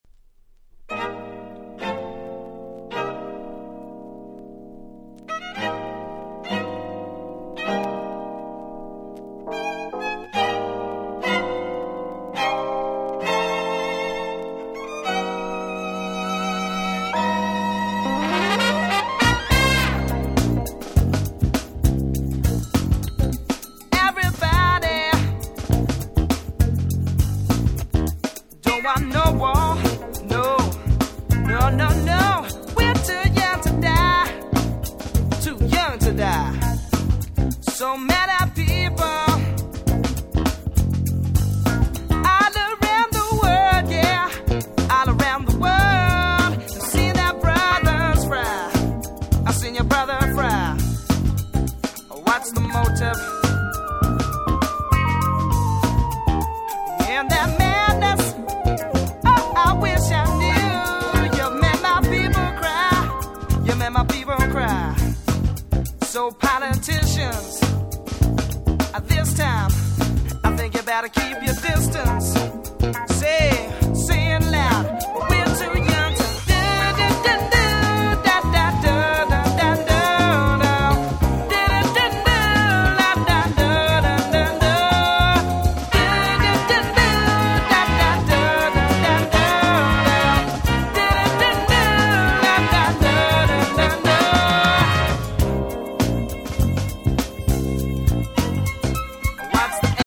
93' Super Hit Acid Jazz/UK Soul !!